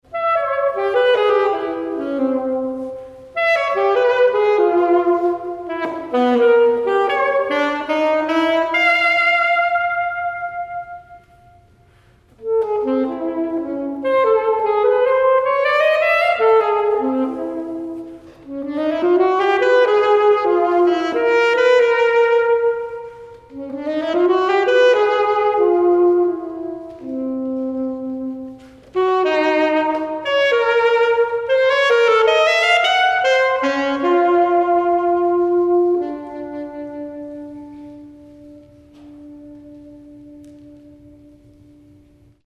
Saxophone solo